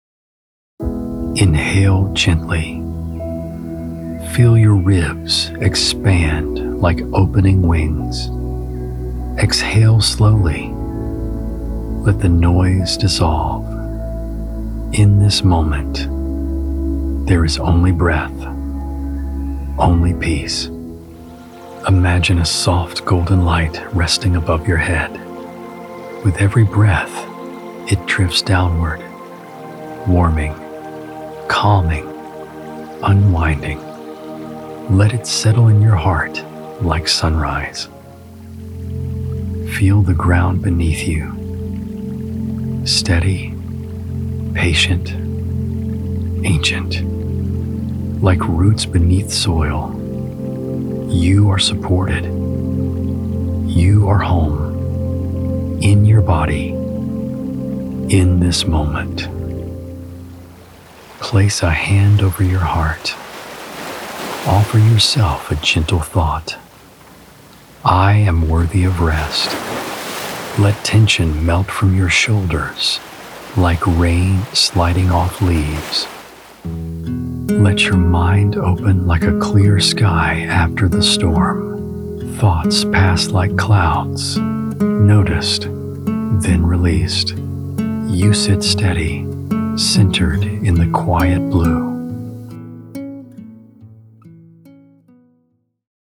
Conversational Male Voice-Commercial, Corporate Narration & E-Learning
Meditation/Wellness- Calm, grounded delivery suited for meditation or wellness content.